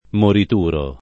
[ morit 2 ro ]